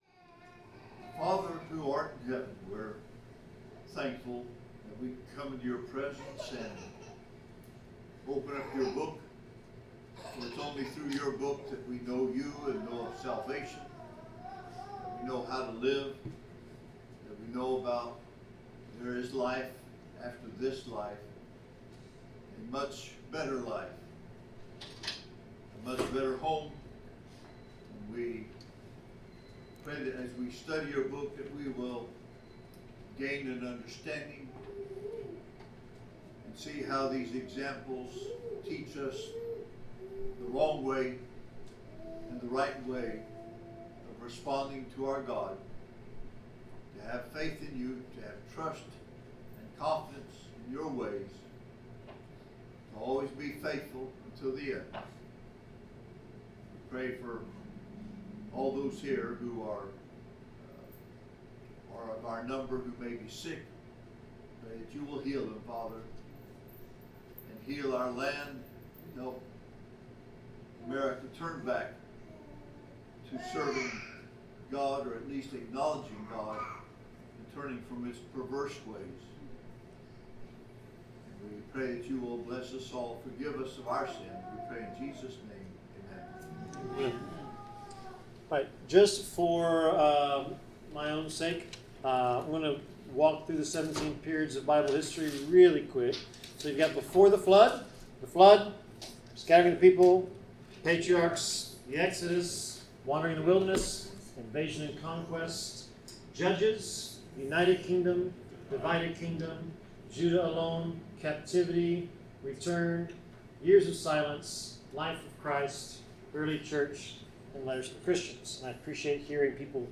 Bible class: 1 Kings 15-16 (Shifting Houses)
Service Type: Bible Class